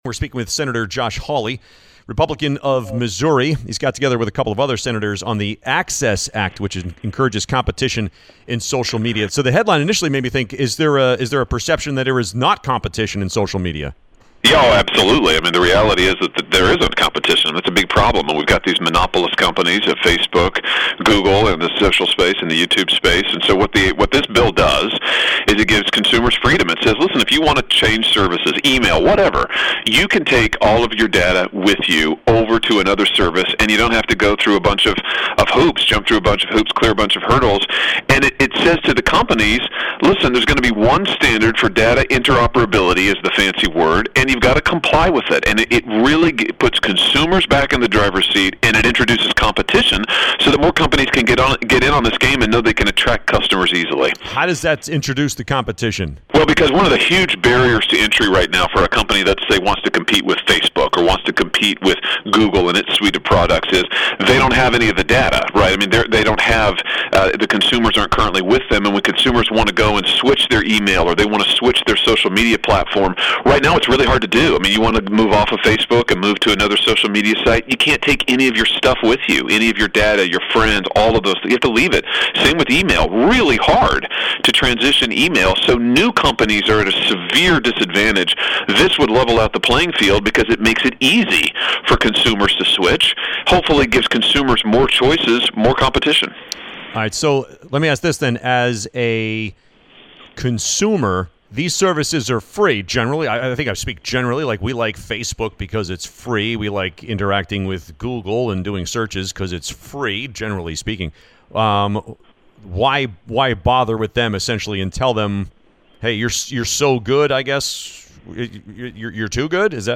A Conversation with Republican Senator Josh Hawley